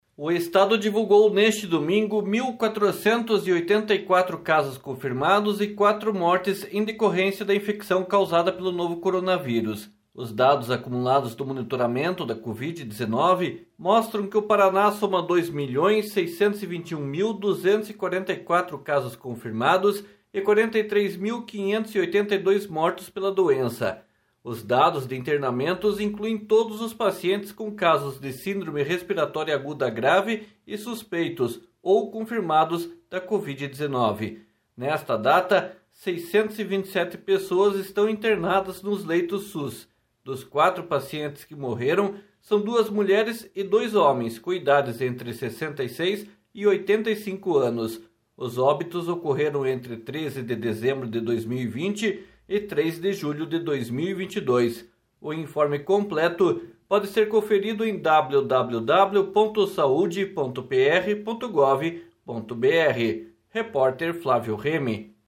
Boletim da Covid-19 registra 1.484 novos casos e quatro óbitos no Paraná